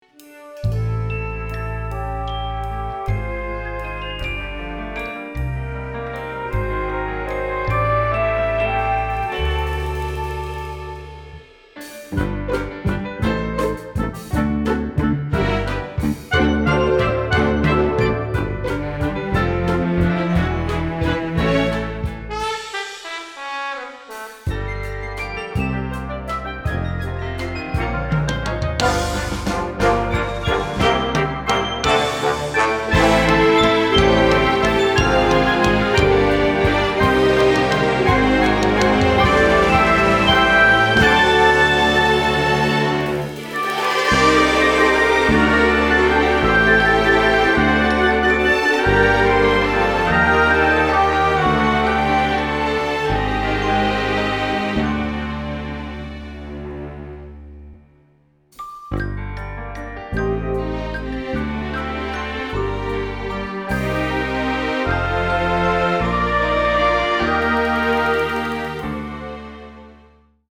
Нажмите для раскрытия... работаю с оркестром на одной машине, с трек-темплейтами, минимум - 180 треков получается, максимум - ок 350.
А вот проект, писанный в риалтайме, фактически без редактуры и работы мышкой.